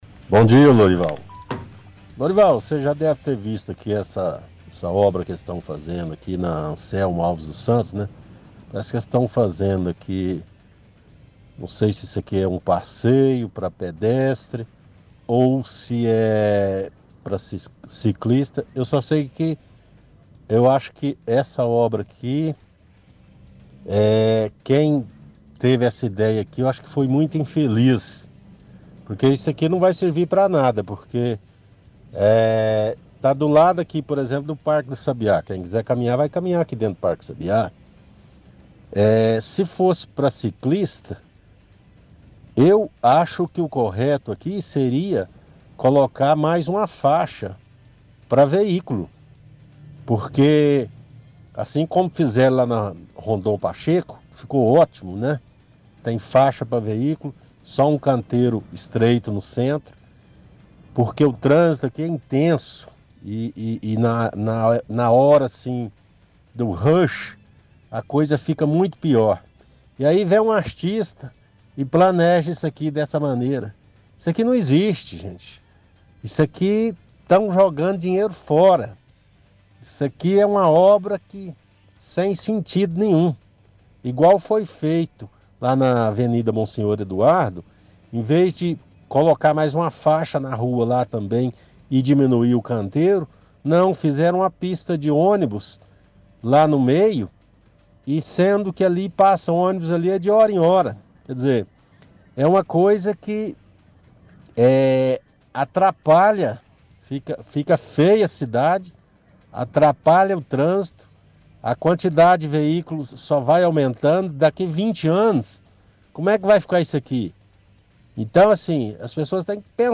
– Áudio antigo onde ouvinte reclama de obra de ciclovia na Anselmo Alves dos Santos, diz que quem teve essa ideia é muito infeliz.